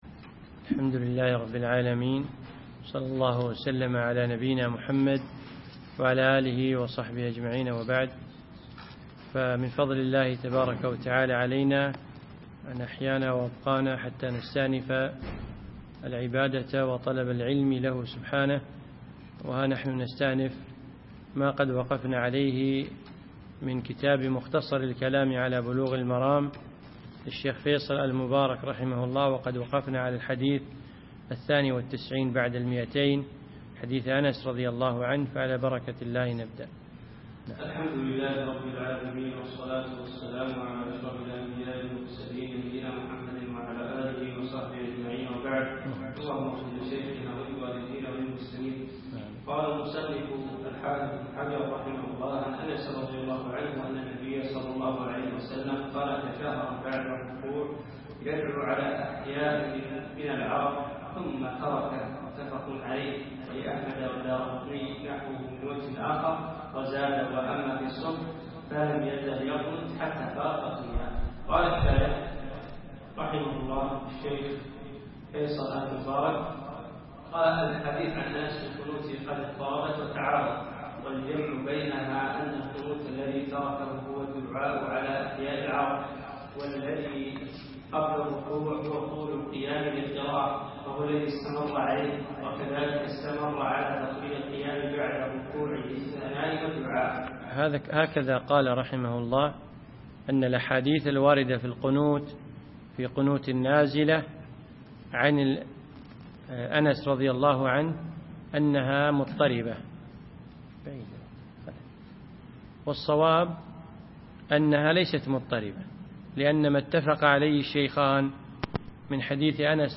يوم الأحد 2 8 2015 في مسجد أحمد العجيل القصور
الدرس الثالث عشر